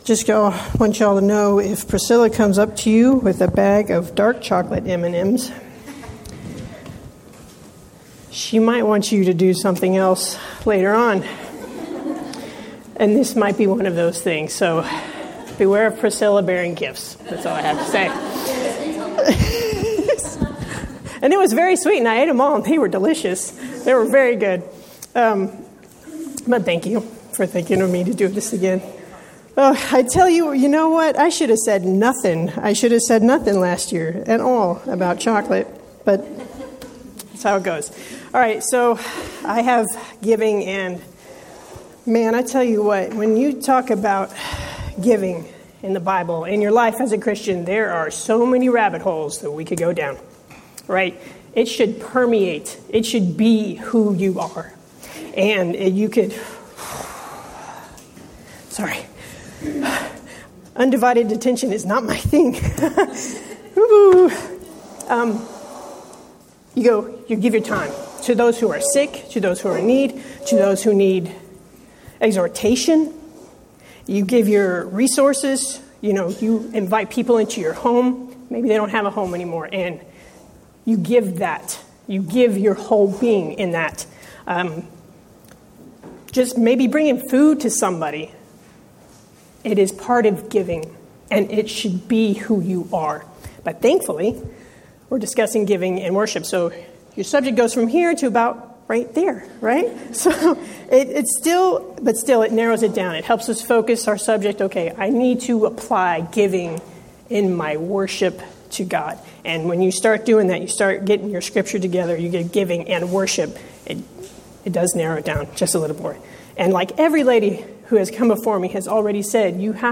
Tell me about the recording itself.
Event: 5th Annual Women of Valor Ladies Retreat